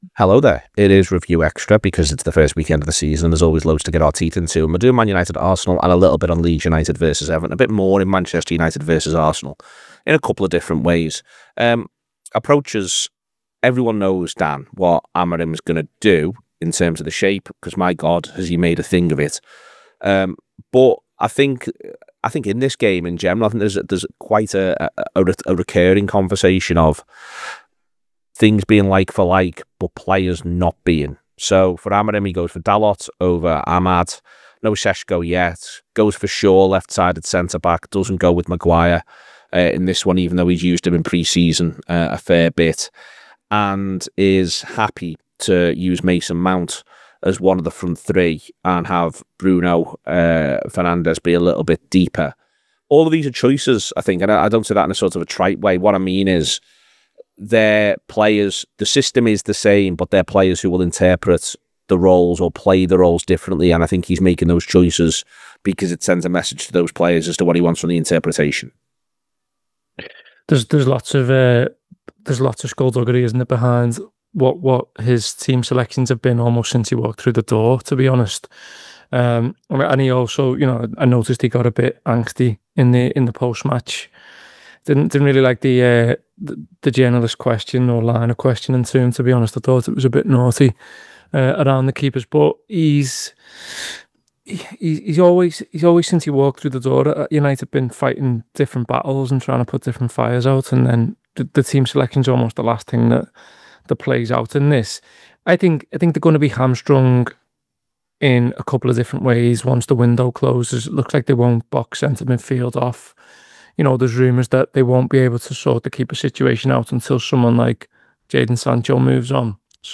Below is a clip from the show – subscribe for more review chat..